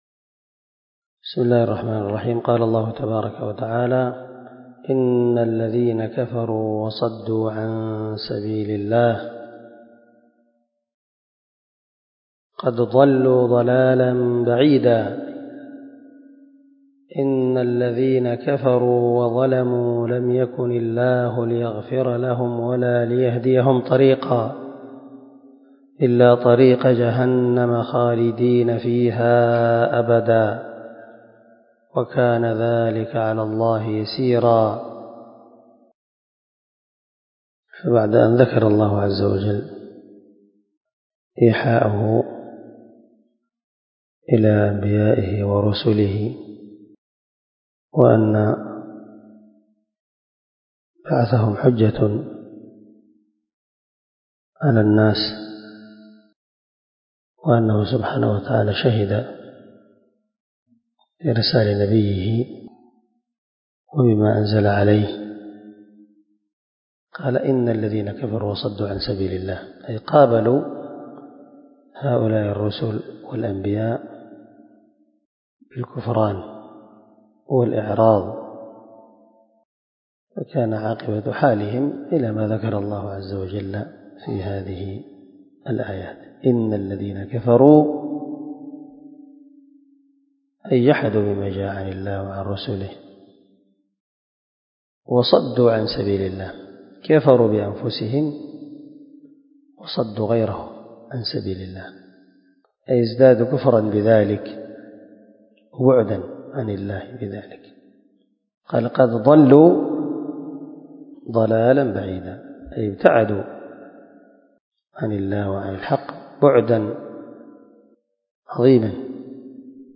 328الدرس 96 تفسير آية ( 167 - 169 )من سورة النساء من تفسير القران الكريم مع قراءة لتفسير السعدي